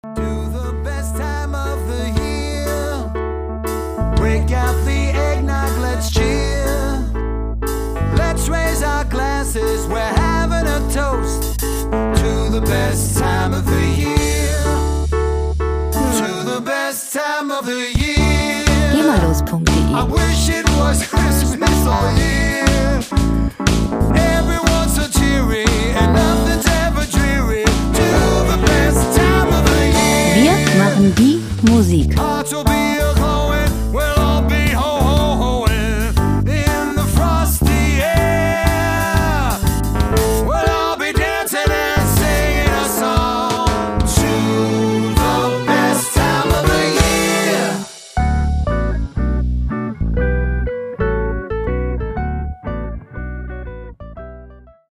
Gemafreie moderne Weihnachtslieder
Musikstil: Christmas Shuffle
Tempo: 120 bpm
Tonart: D-Dur
Charakter: lustig, frech
Hörprobe [890 KB]